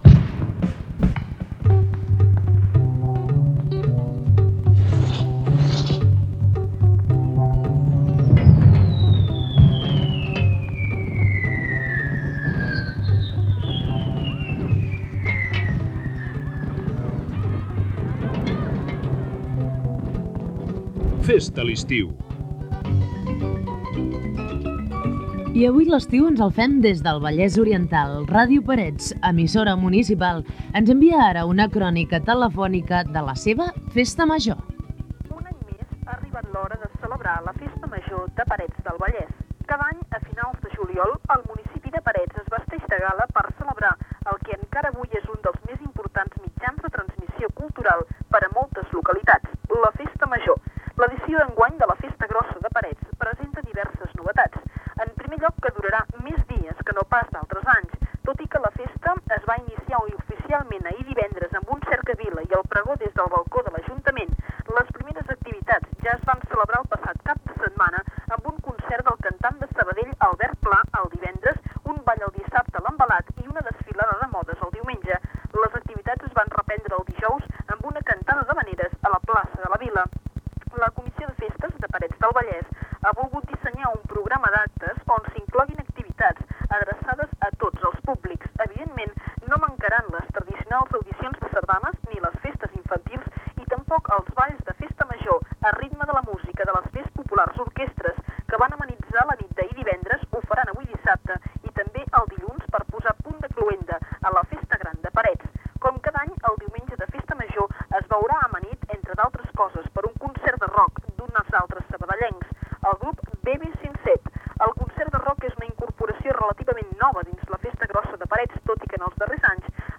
Fes-te l'estiu, festa major de Parets del Vallès, promo Ràdio 4, publicitat, indicatiu del programa i tema musical.
Entreteniment
FM